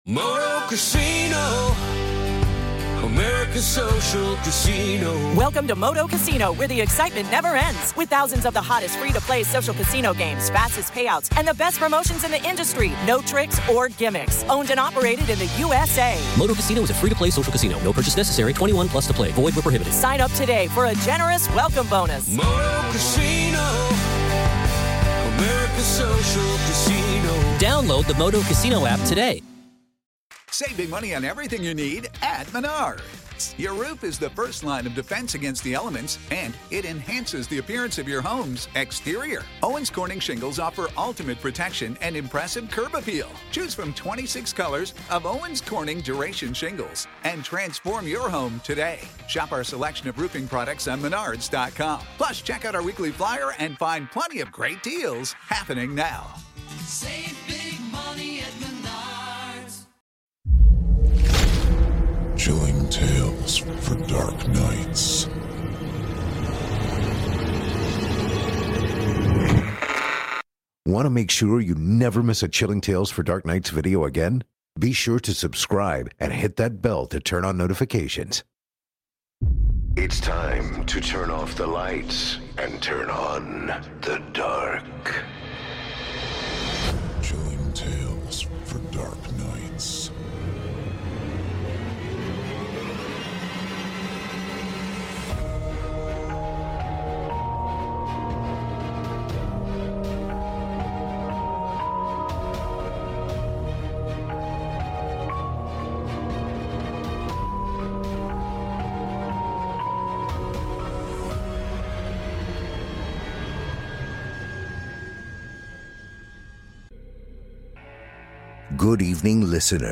A Horror Anthology and Scary Stories Series Podcast